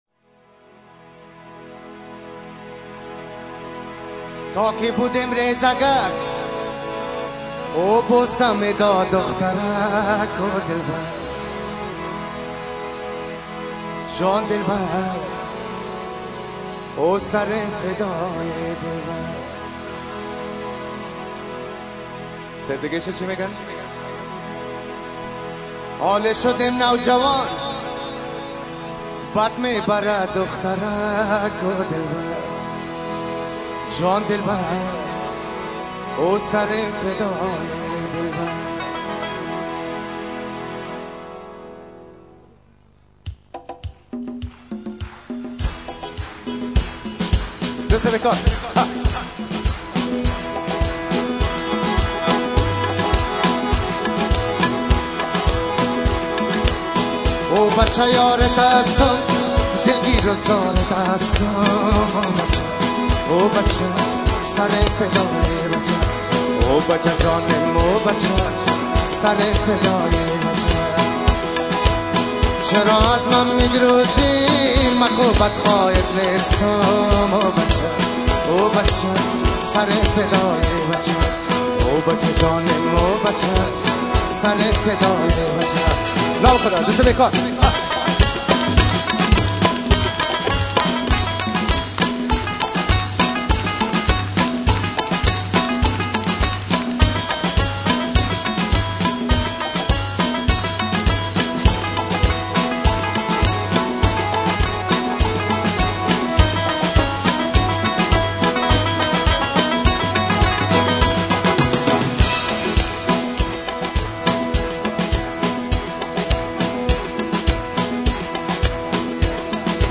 Concert (germany)